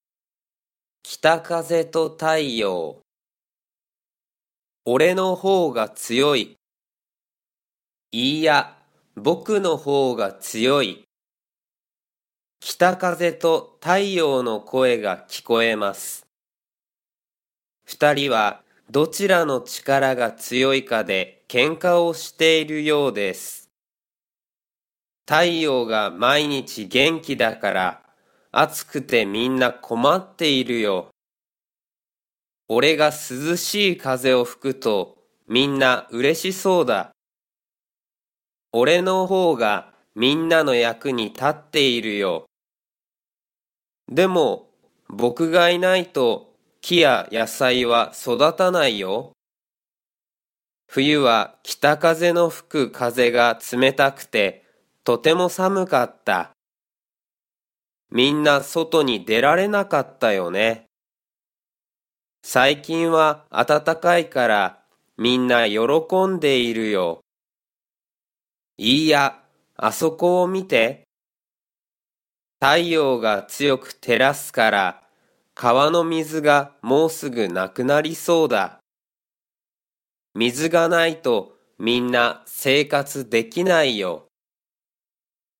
Japanese Graded Readers: Fairy Tales and Short Stories with Read-aloud Method
Slow Speed